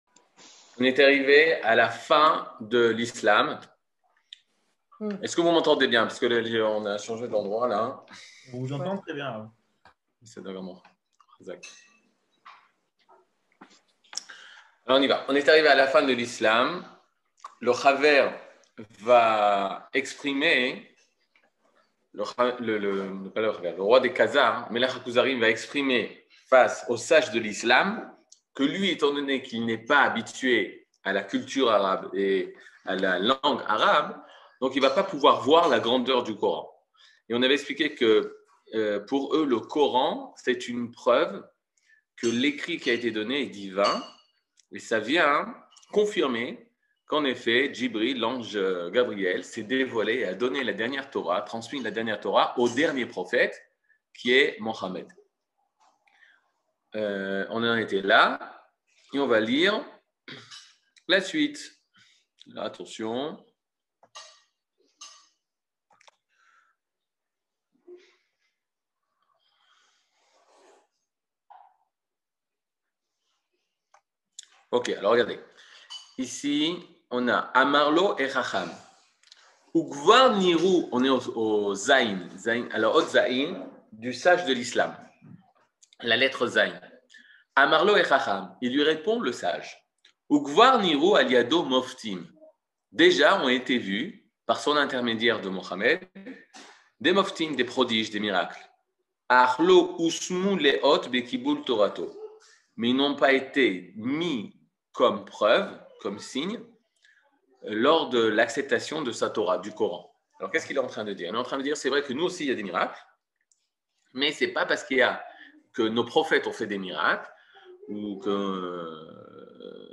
Catégorie Le livre du Kuzari partie 13 00:59:14 Le livre du Kuzari partie 13 cours du 16 mai 2022 59MIN Télécharger AUDIO MP3 (54.23 Mo) Télécharger VIDEO MP4 (107.17 Mo) TAGS : Mini-cours Voir aussi ?